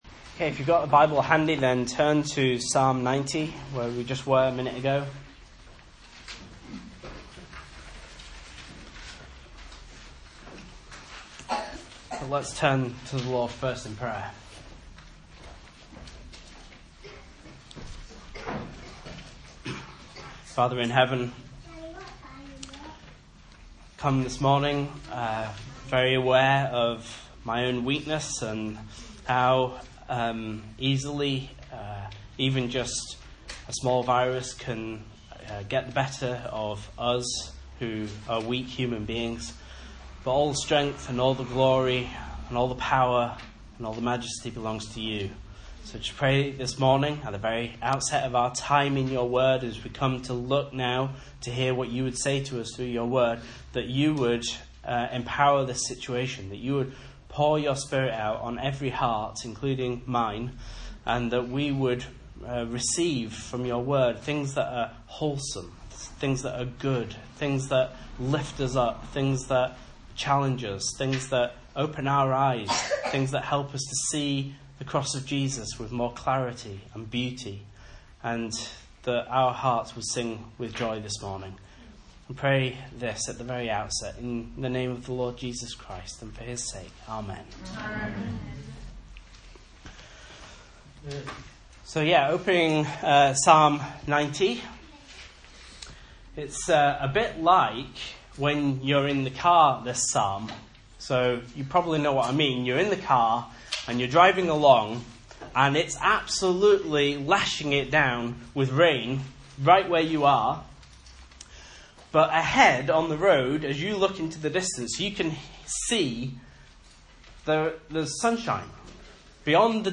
Message Scripture: Psalm 90 | Listen